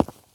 footstep_concrete_walk_21.wav